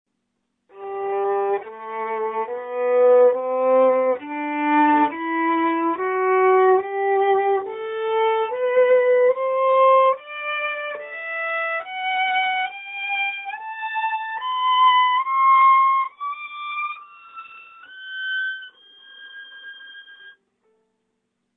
というわけで、簡単なｽｹｰﾙで音色の違いを確認してみました！
2本めの弓(ｶｰﾎﾞﾝﾌｧｲﾊﾞｰ・たぶん中国製)
1本めと3本めは響きのﾚﾍﾞﾙは同じくらい。2本めが一番響きますね？
ﾃﾝｼｮﾝに負けて安定感がなく、音がﾌﾞﾚてしまいますけれど、